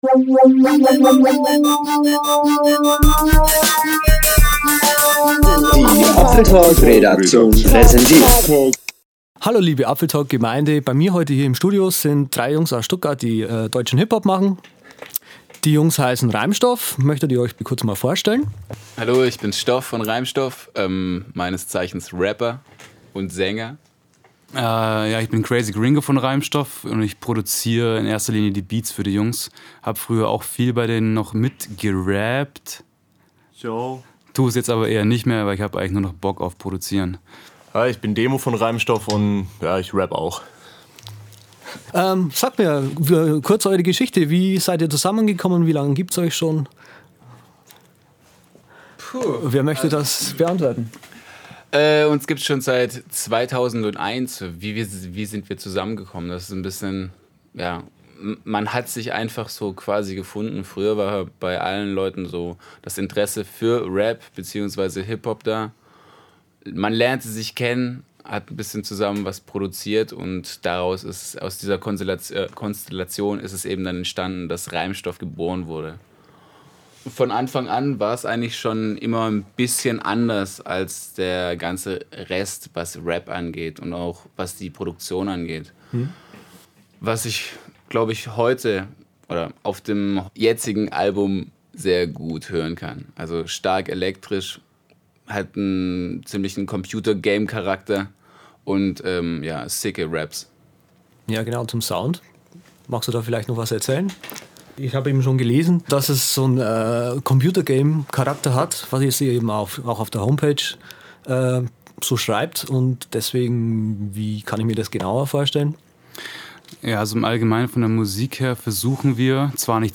Reimstoff | Interview
Sie haben gerade ihr drittes Album namens "In Stereo" fertig produziert, weswegen es genügend Gesprächsstoff für Reimstoff gab. Ich habe die 3 Jungs zu mir ins Studio eingeladen und sie ausgequetscht was das Zeug hält.
Keine Angst, die Jungs sind recht lustig es wird euch also nicht langweilig